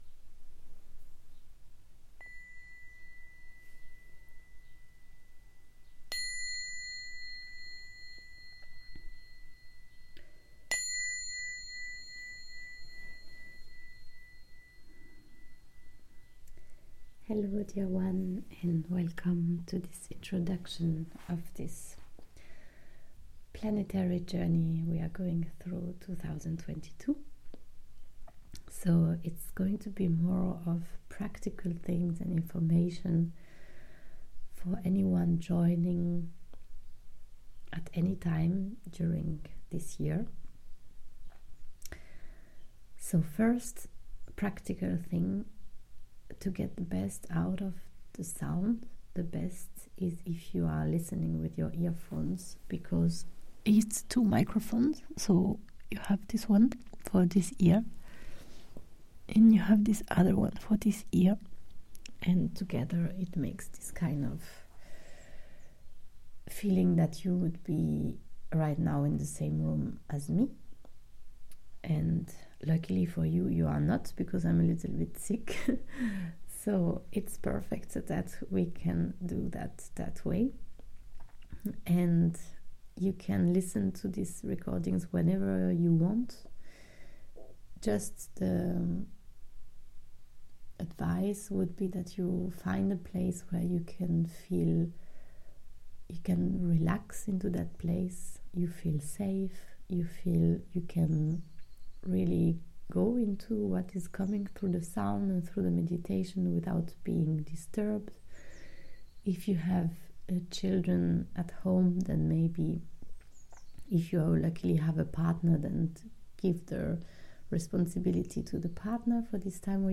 We invite the number of planets of the month to join the sound journeys creating symphonies. Sound Meditations with frequencies of planets